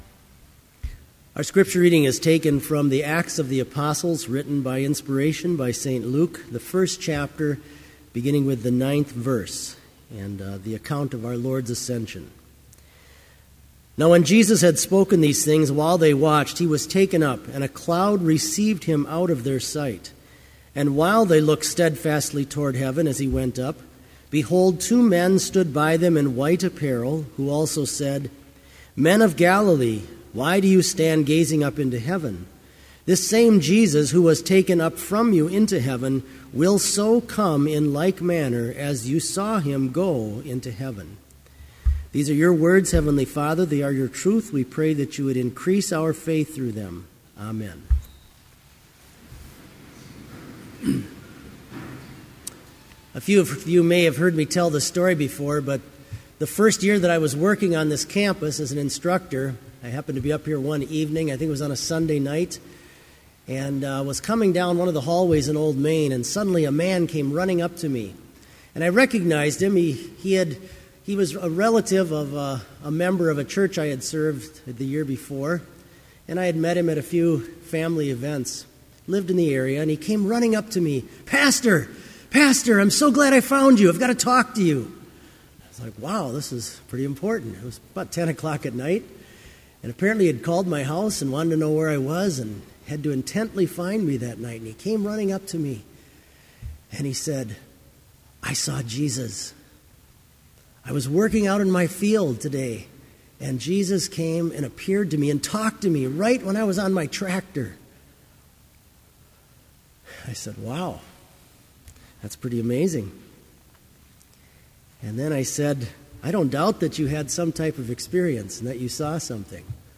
Sermon audio for Chapel - December 10, 2012
Listen Sermon Only Audio file: Sermon Only Order of Service Prelude Hymn 98, Lo!